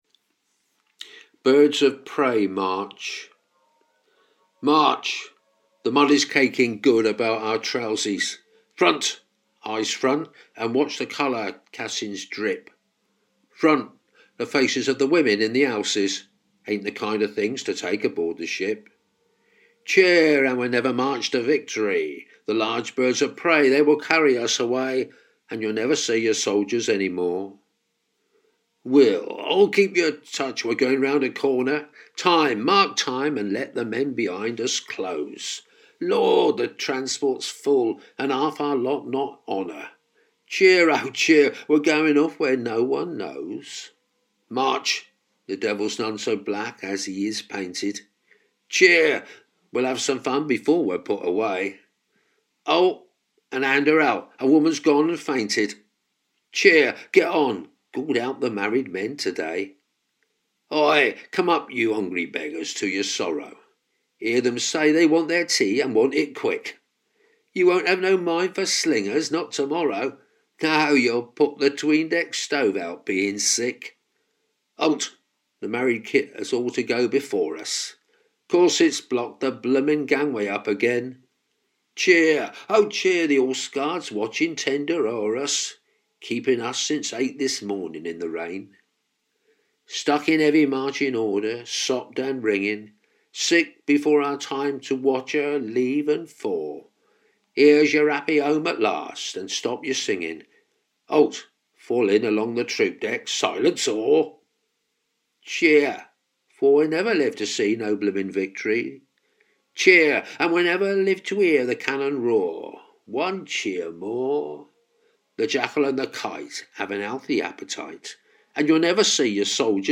Reading Aloud